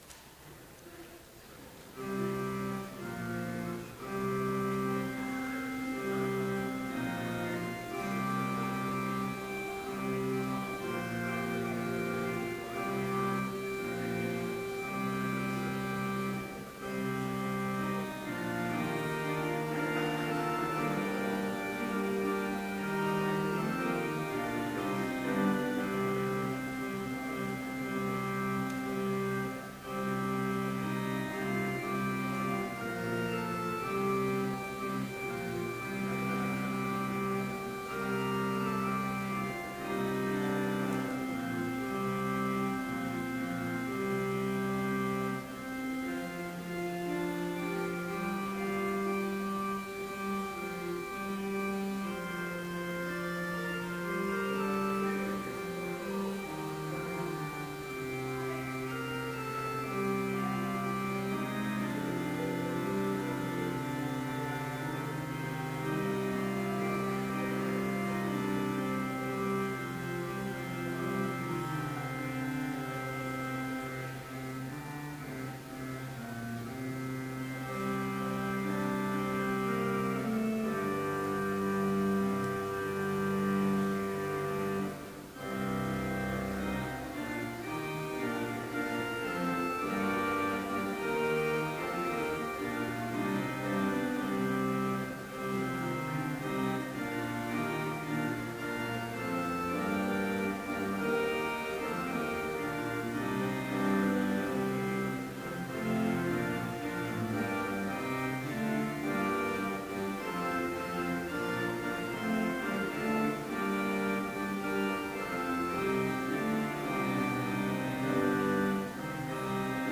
Complete service audio for Chapel - February 14, 2013